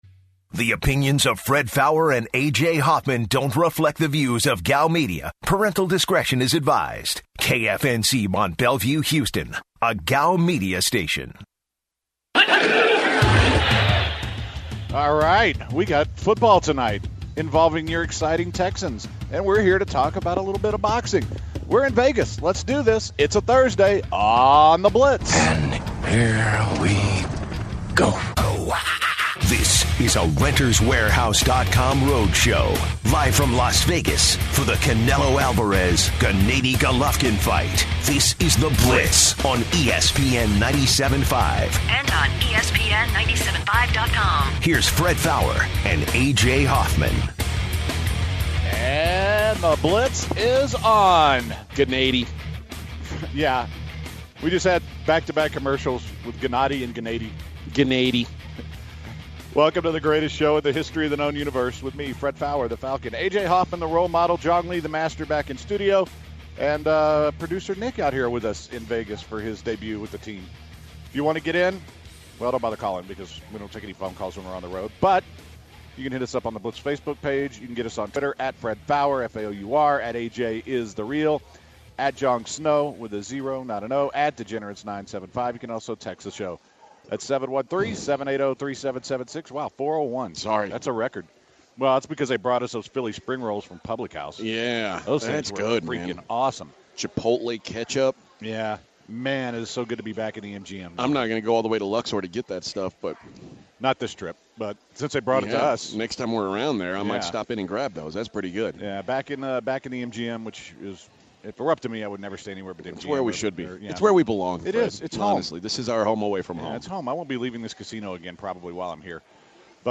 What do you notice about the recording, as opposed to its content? The Blitz comes live from Las Vegas for the Canelo-GGG fight.